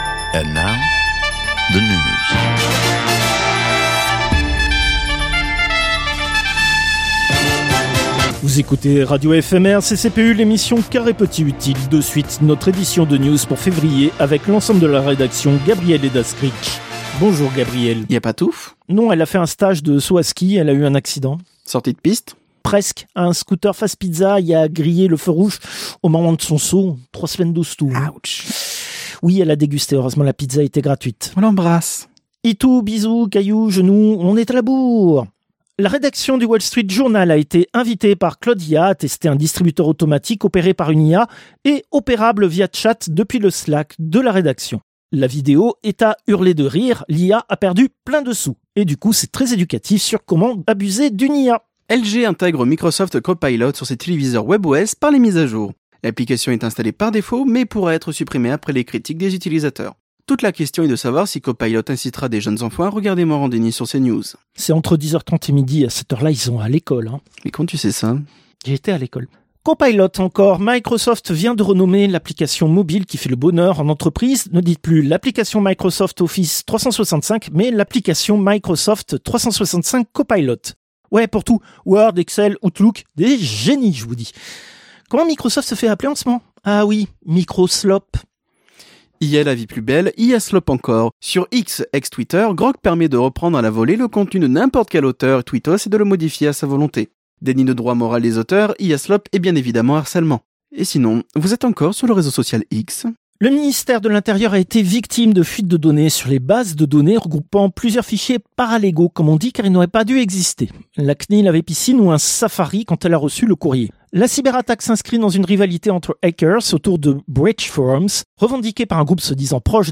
Extrait de l'émission CPU release Ex0231 : lost + found (février 2026).